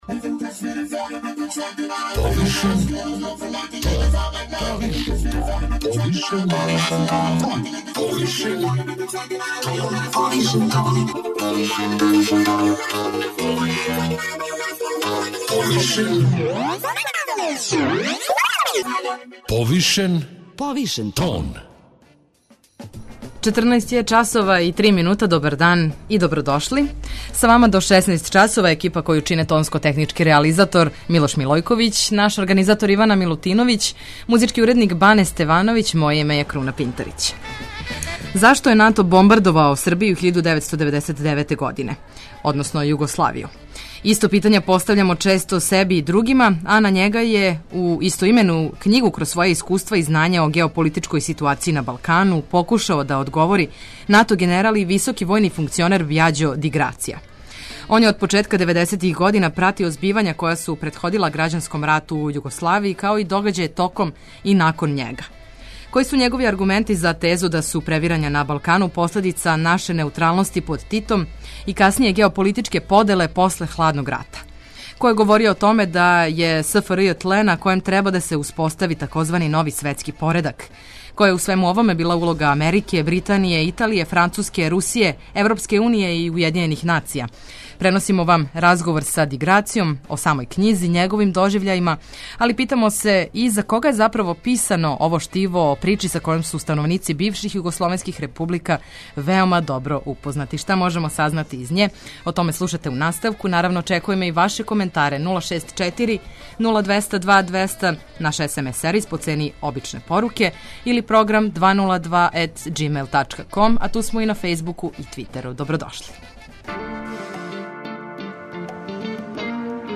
Преносимо вам разговор